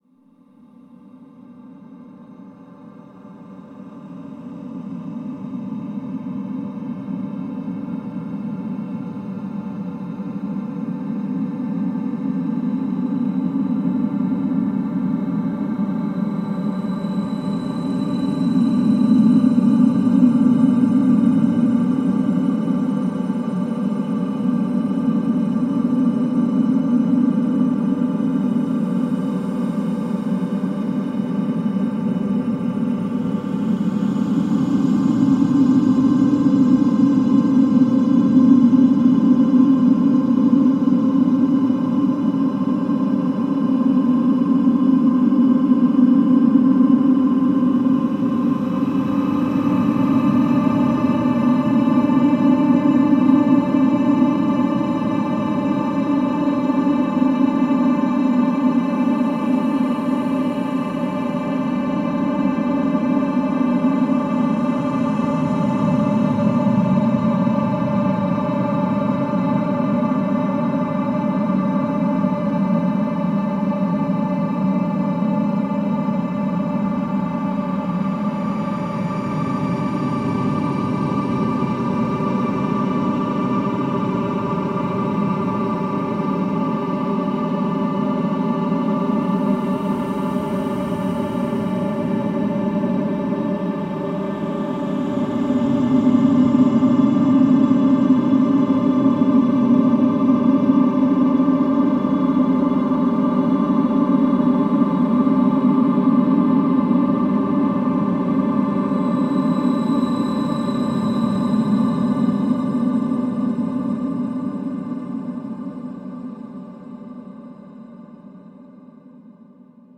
I paul stretched the acoustic guitar sample in audacity and then recorded it into my zoom h1 through mercury x.